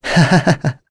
Lucias-vox-Happy1.wav